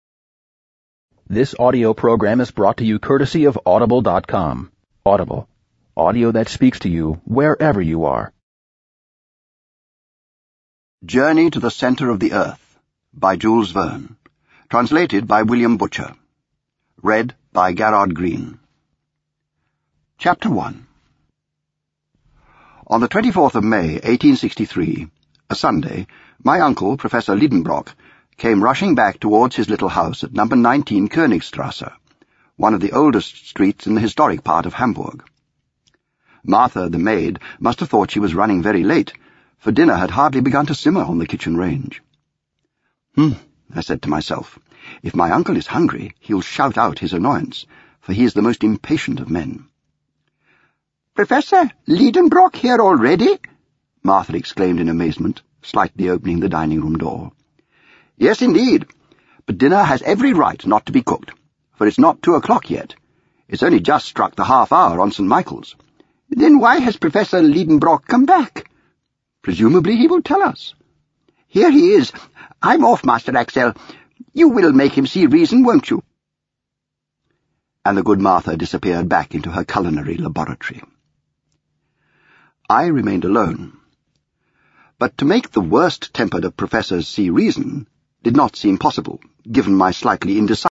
Pirated audio version of the translation, without permission or payment, without the accompanying material or proper acknowledgement of the translator